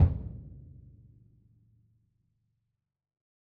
BDrumNewhit_v5_rr2_Sum.wav